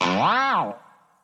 Boing (5).wav